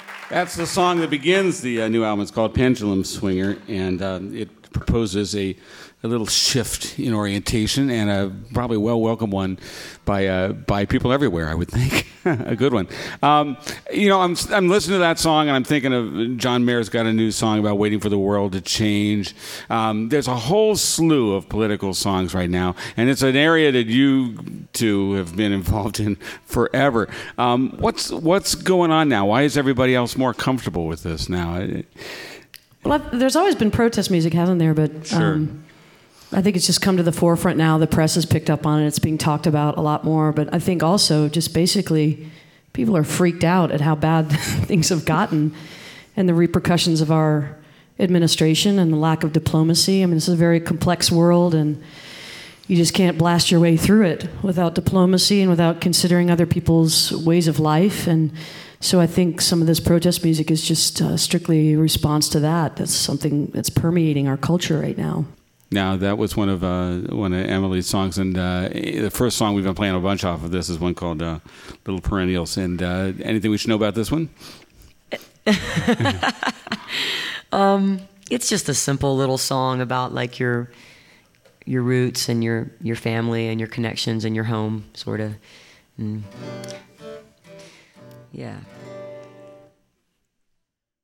(band show)
02. interview (1:31)